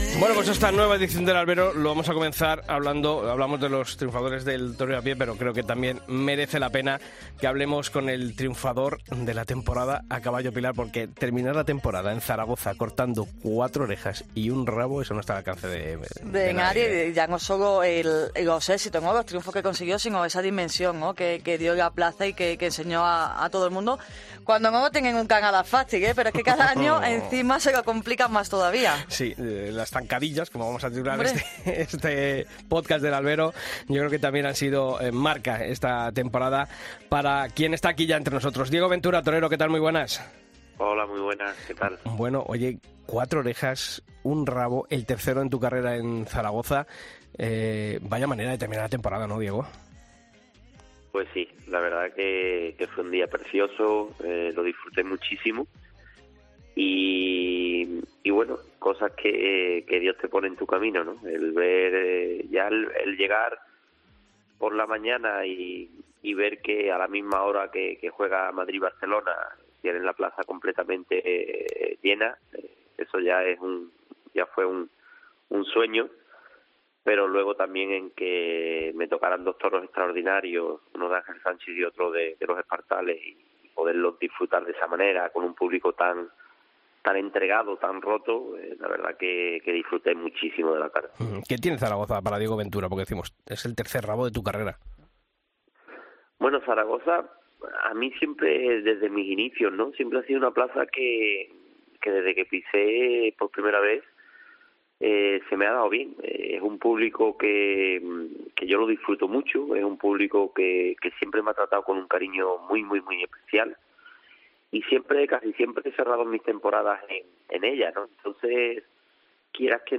Escucha la entrevista a Diego Ventura en El Albero